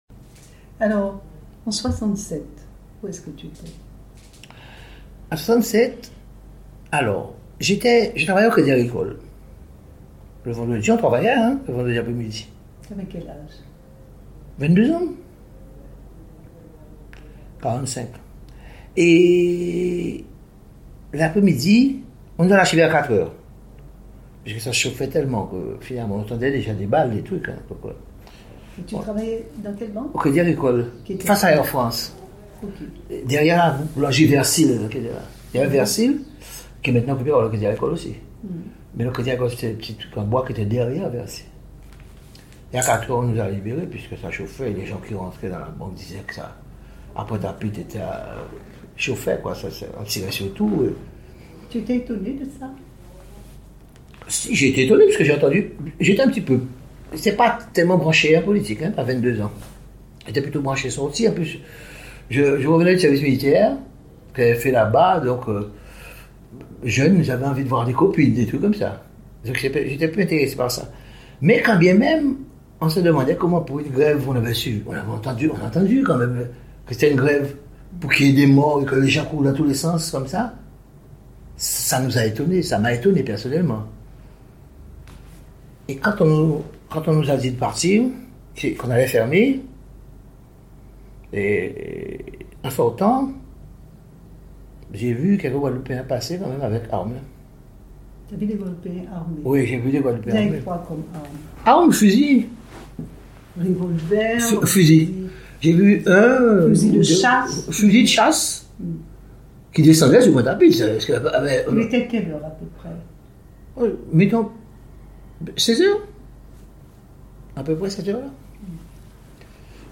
Témoignage sur les évènements de mai 1967 à Pointe-à-Pitre.
Intégralité de l'interview.